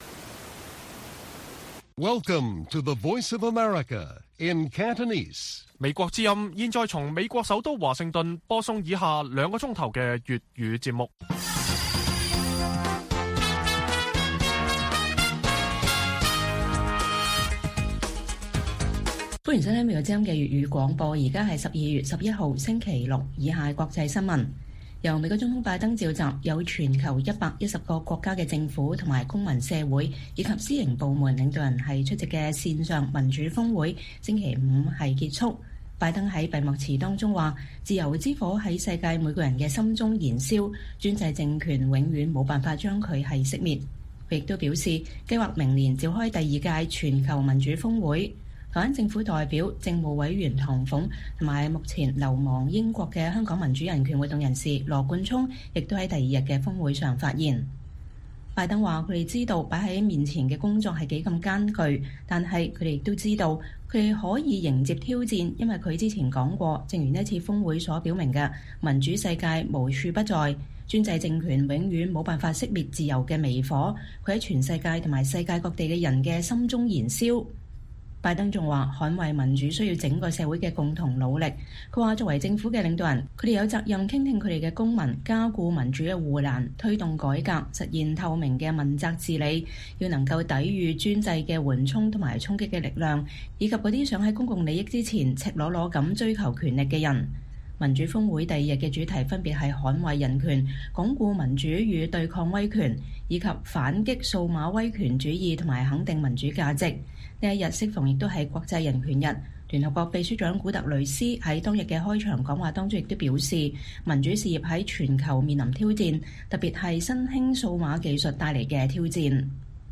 粵語新聞 晚上9-10點: 民主峰會落幕 拜登稱自由之火永不熄滅